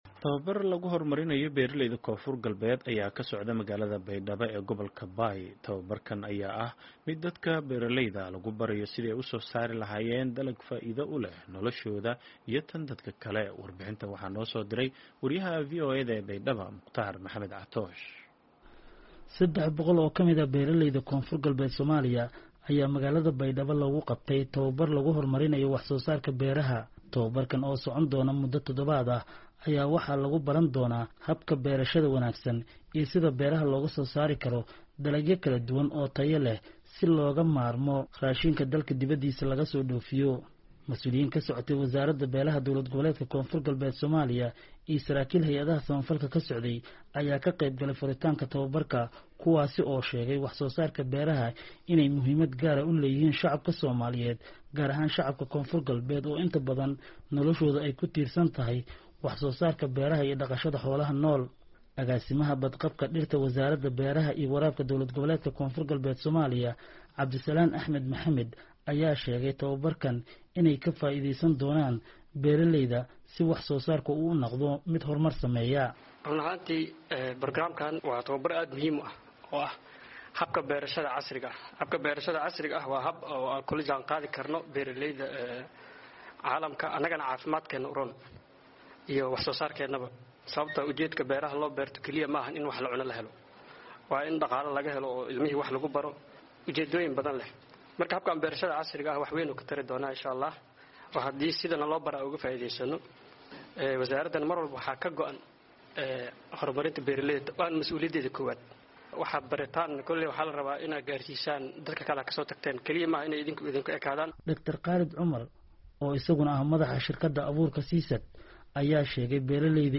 Warbxintan waxaa magaalada Baydhaba ka soo diray